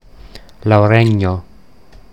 Standarditalienische Form
[lauˈrɛɲo]
Lauregno_Standard.mp3